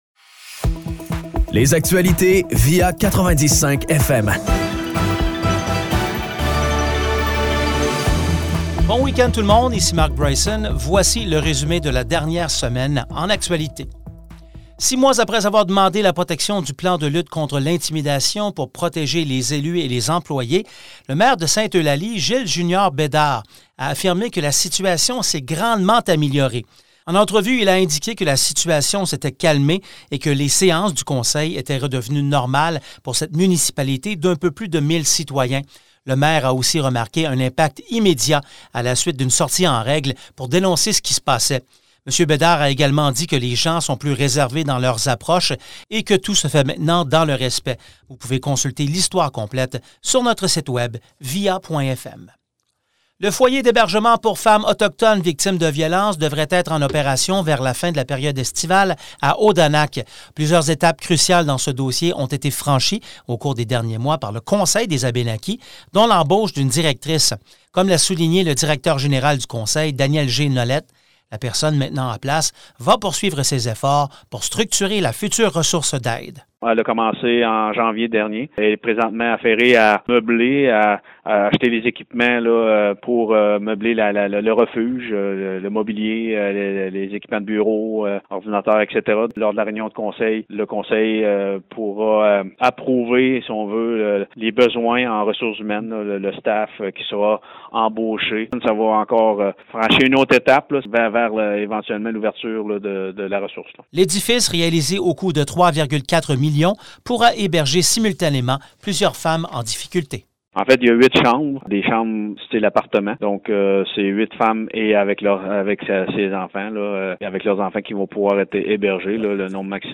Pour ce résumé de la semaine, VIA 90.5 FM s’est entretenu avec le maire de Sainte-Eulalie, Gilles Jr Bédard, sur l’ancien climat toxique qui régnait et la situation à l’urgence de Fortierville s’est améliorée, mais il reste encore du travail à faire.